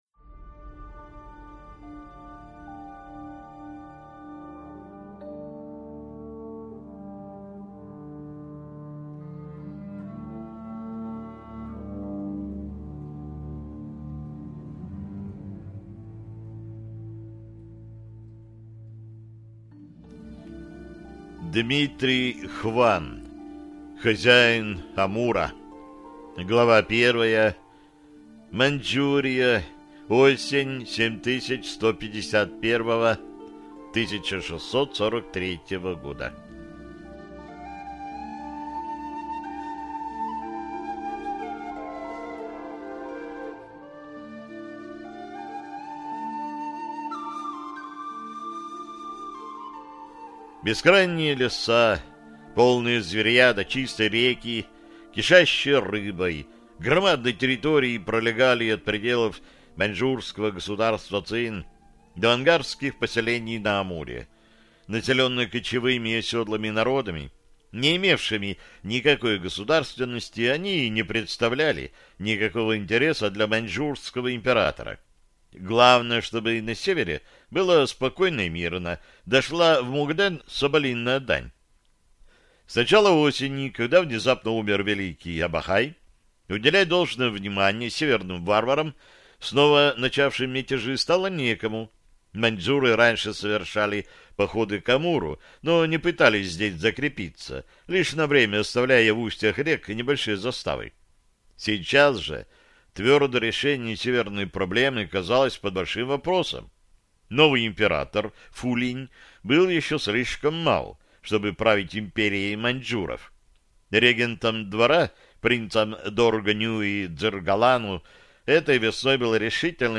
Аудиокнига Хозяин Амура | Библиотека аудиокниг